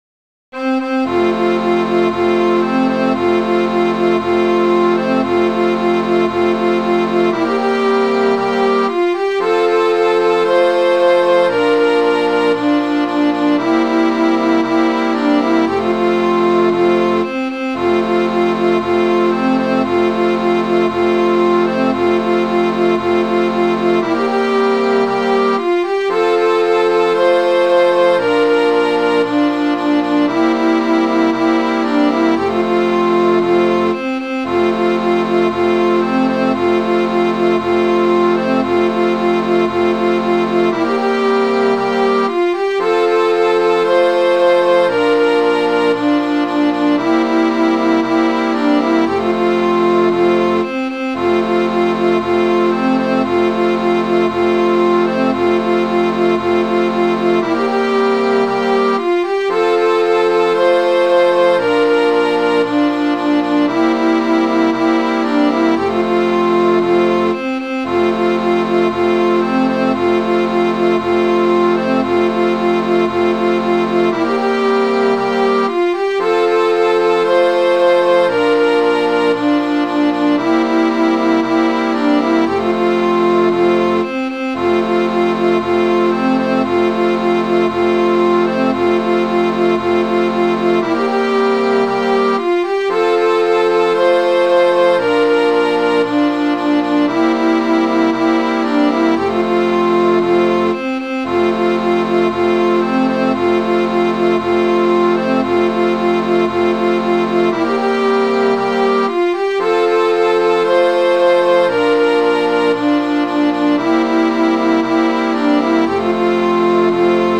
Midi File, Lyrics and Information to The Constitution and the Guerriere